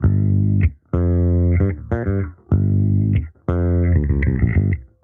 Index of /musicradar/sampled-funk-soul-samples/95bpm/Bass
SSF_JBassProc1_95G.wav